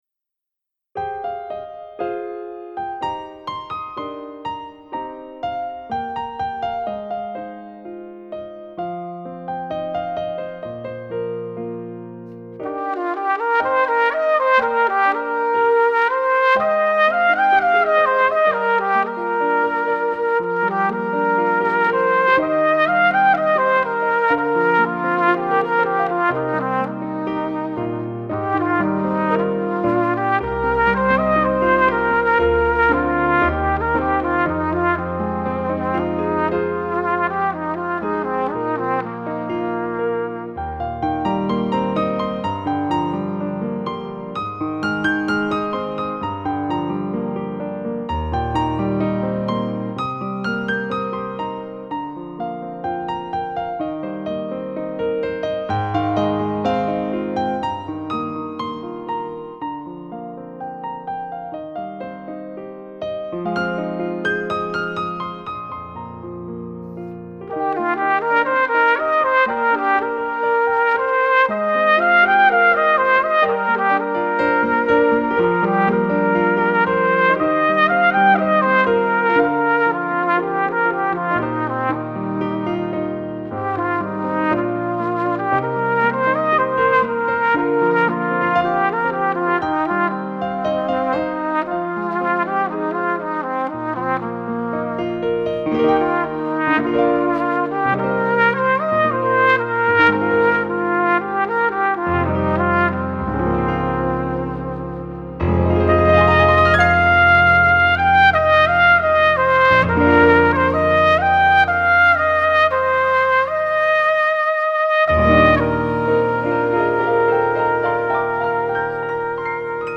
0275-小号名曲茉莉花.mp3